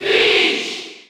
Category: Peach (SSBU) Category: Crowd cheers (SSBU) You cannot overwrite this file.
Peach_Cheer_French_NTSC_SSBU.ogg.mp3